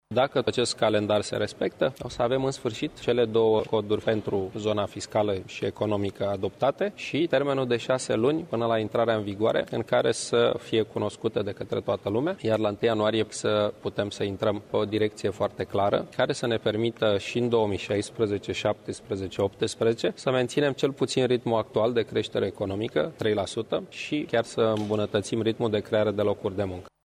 Declarația aparține premierul Victor Ponta.